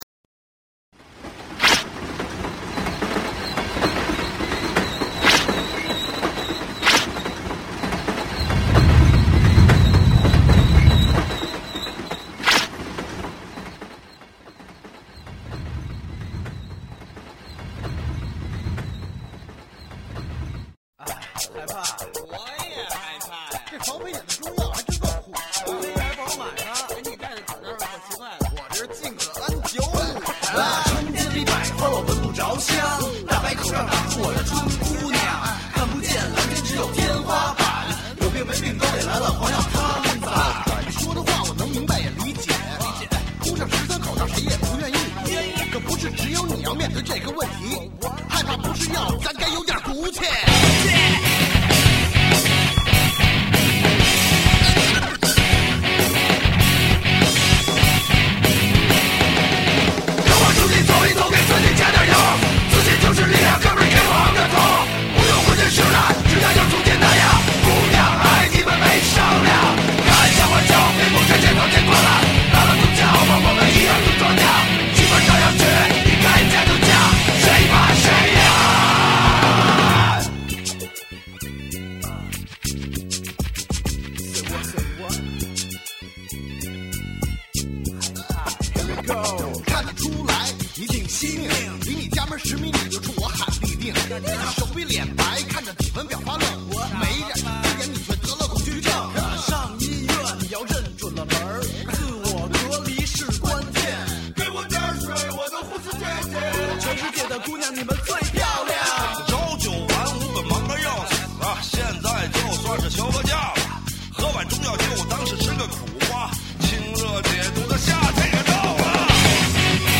摇滚风格的抗非歌曲音乐录影带
吉他
Scratch
民乐